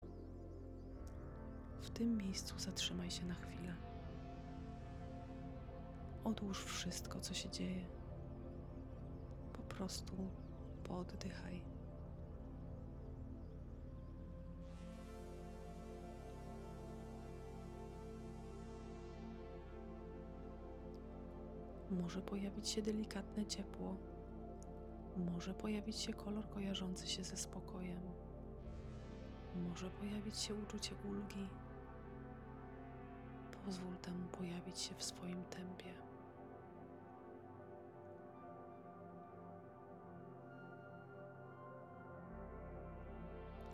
• Sesję audio, trwającą kilkanaście minut, prowadzącą przez proces regulacji emocji.
1-probka-relaksacji-mp3cut.net_.mp3